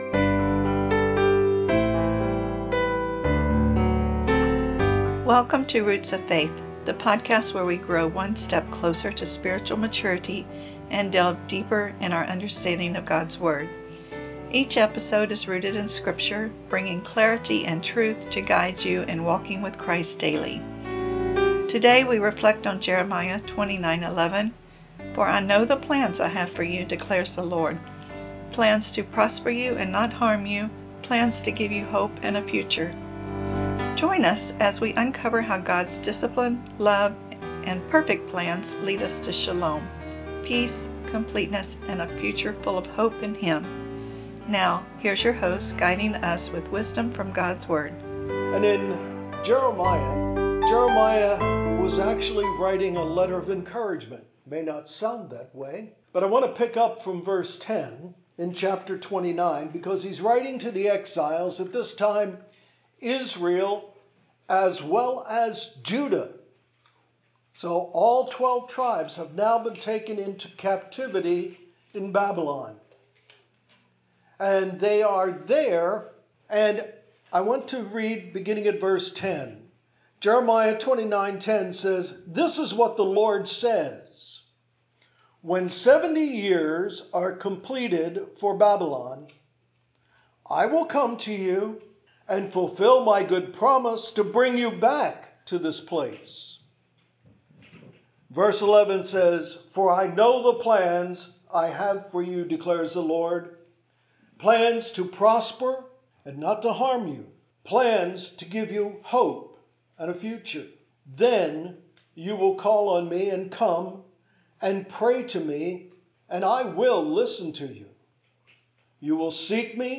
Discover how God's discipline, grounded in His love and perfect plan, leads to restoration and spiritual growth. This sermon explores Jeremiah 29:10-13, revealing God's promise of hope, peace, and a future for those who seek Him wholeheartedly.